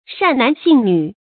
善男信女 注音： ㄕㄢˋ ㄣㄢˊ ㄒㄧㄣˋ ㄋㄩˇ 讀音讀法： 意思解釋： 原指皈依佛教的人。后泛指虔誠信奉佛教的男女。